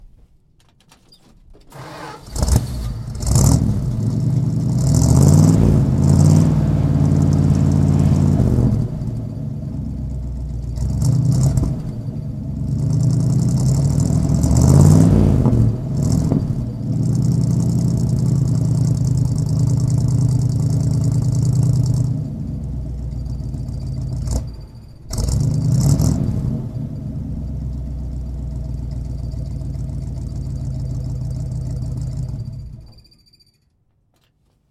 Ambient sound may start automatically when allowed by your browser.
revving-engines.mp3